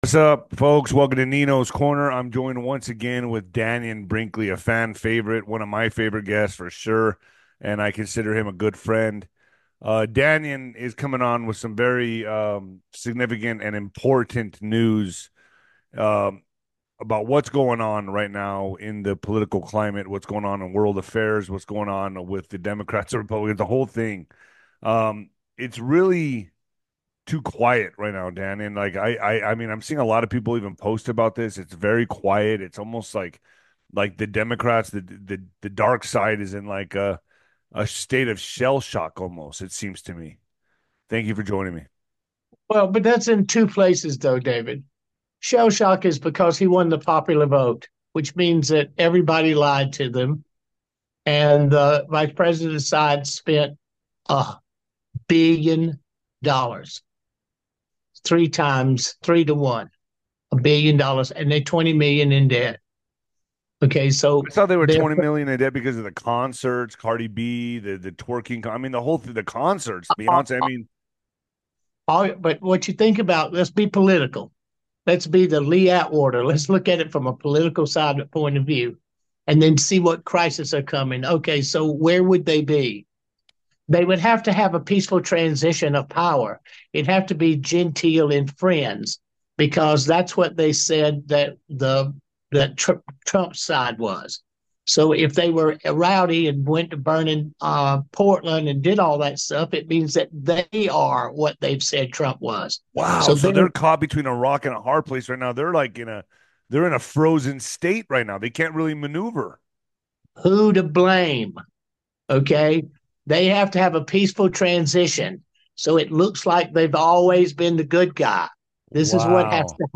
He explains why Democrats might be surprised by recent votes and their money troubles. The chat also highlights the need for smooth power shifts and possible disruptions.